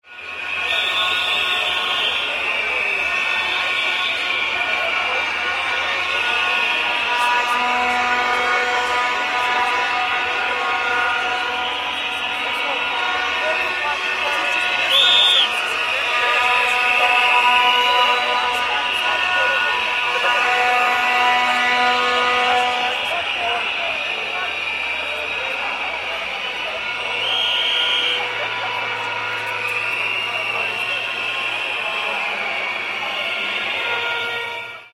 Crowd-riot-sound-effect.mp3